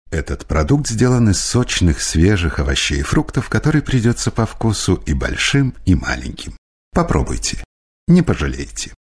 Native speaker Male 50 lat +
Nagranie lektorskie